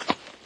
PixelPerfectionCE/assets/minecraft/sounds/mob/horse/soft1.ogg at mc116